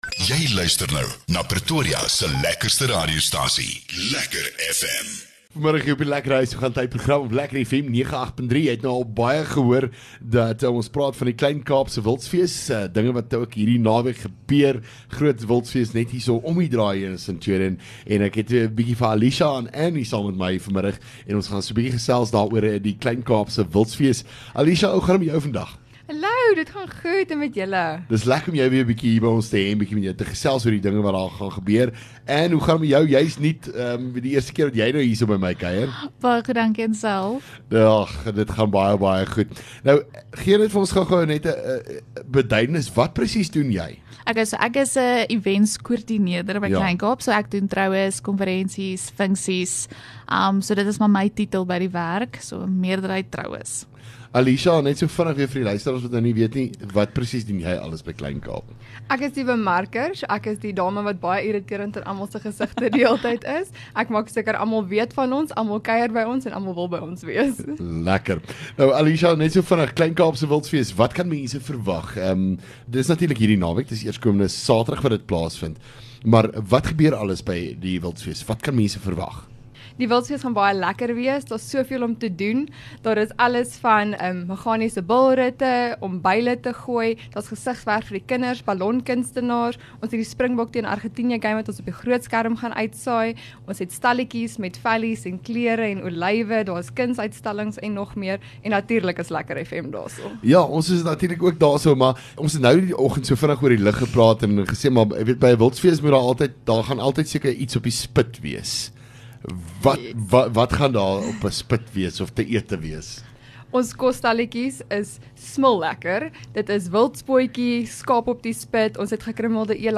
LEKKER FM | Onderhoude 26 Jul Kleinkaap Wildsfees